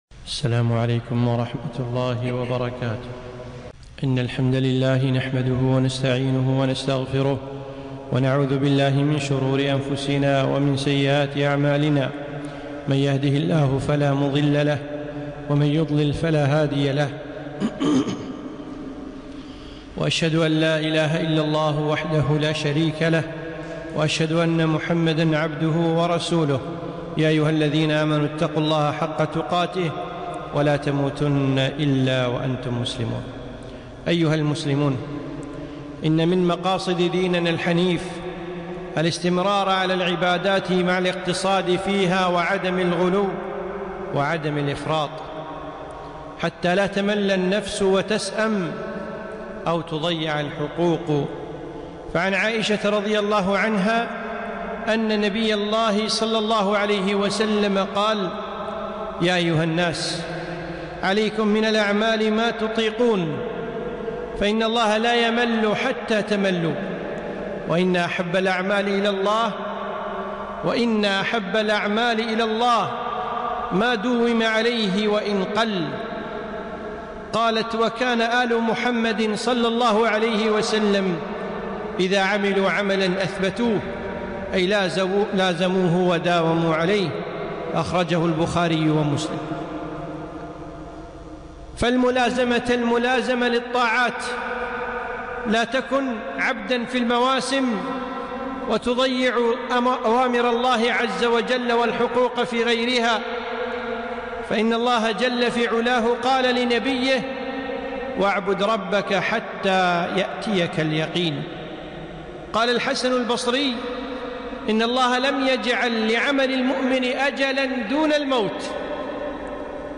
خطبة - أحب الأعمال إلى الله